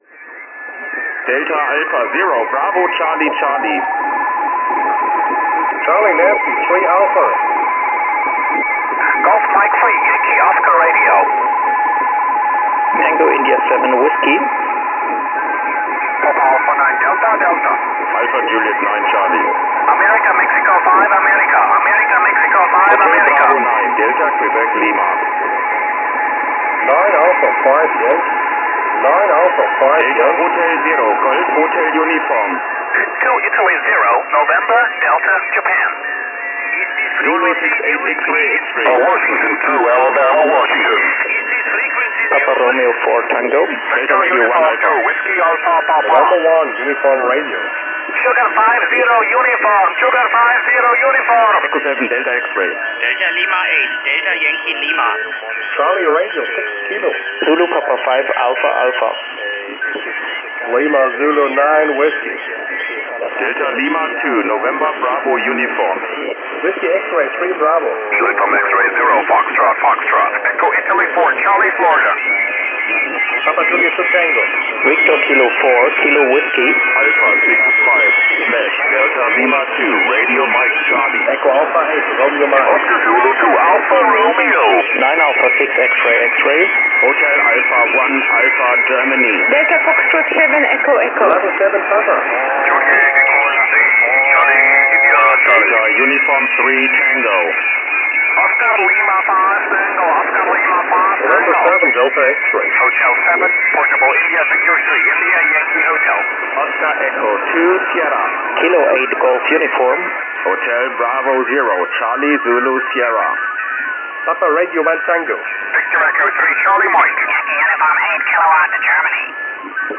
Der Pile-Up-Wettbewerb wird an allen Tagen gut angenommen und manch einer kommt nicht nur wegen der Temperatur in der Messehalle ins Schwitzen. Aus dem Stimmengewirr die richtigen Rufzeichen herauszuhören ist alles andere als einfach – das war nicht überraschend.
2023_bcc-challenge_freitag.mp3